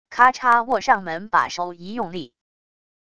咔嚓握上门把手一用力wav音频